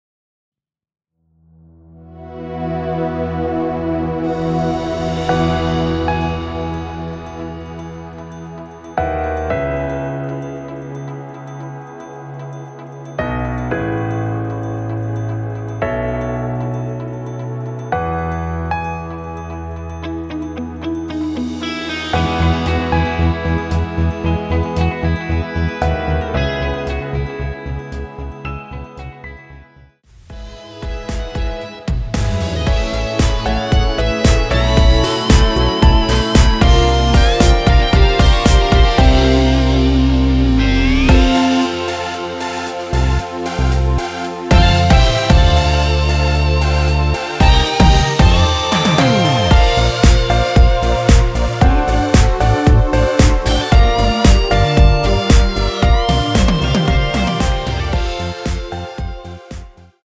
원키에서(+3)올린 MR입니다.
앞부분30초, 뒷부분30초씩 편집해서 올려 드리고 있습니다.
중간에 음이 끈어지고 다시 나오는 이유는